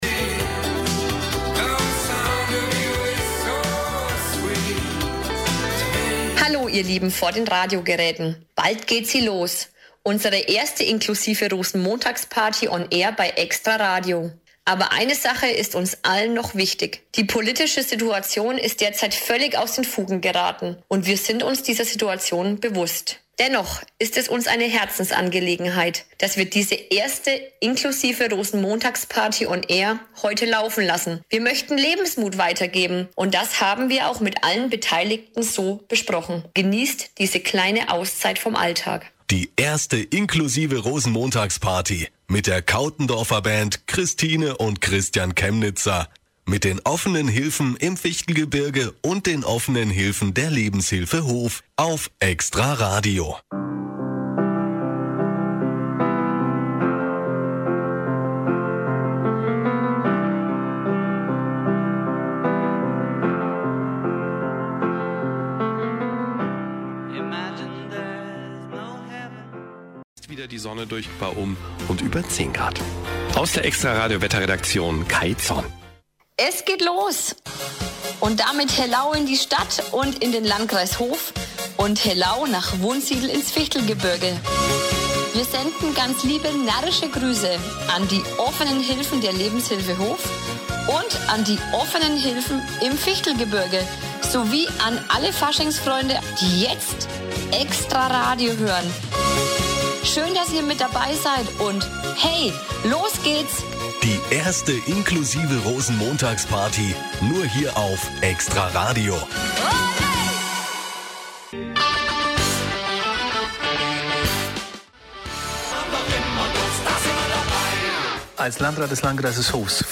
Die inklusive Party im Radio findet 2022 am Rosenmontag statt!
Mitschnitt-Inklusive-Rosenmontagsparty-2022.mp3